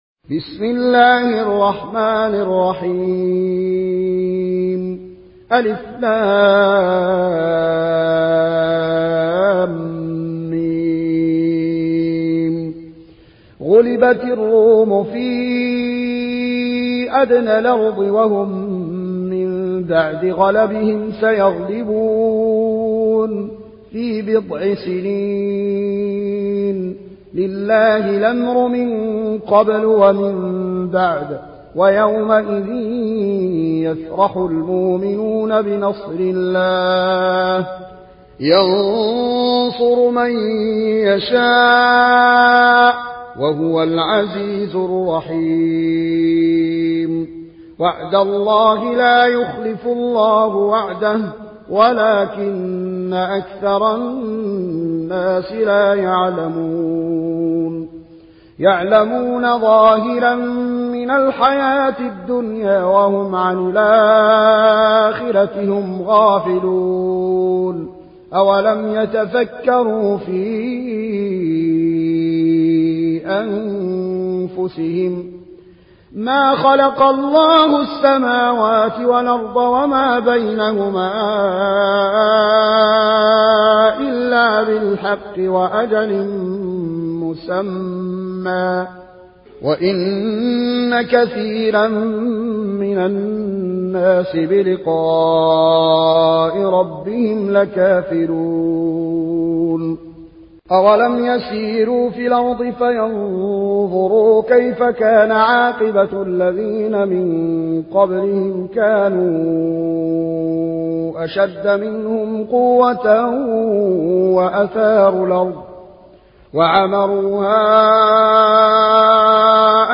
ورش عن نافع